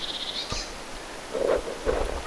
vähän tuollainen hiukan erilainen peipon ääni?
tuollainen_vahan_erilainen_ilmeinen_peipon_aani_lentoaaninormaalisti.mp3